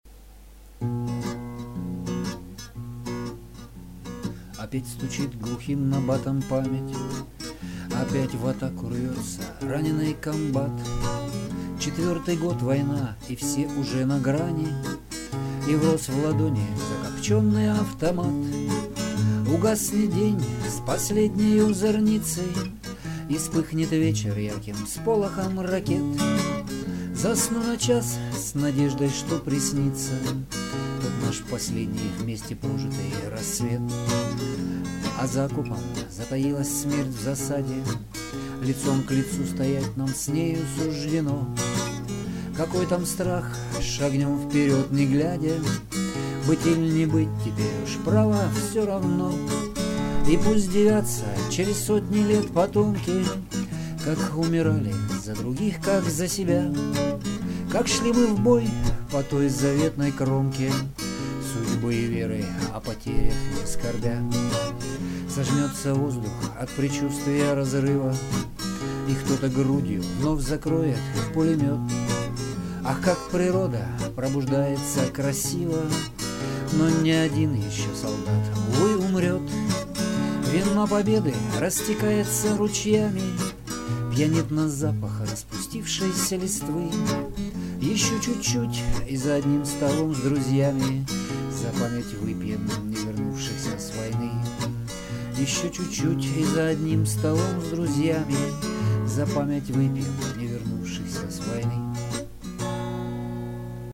Сочиняю-играю-пою - сам.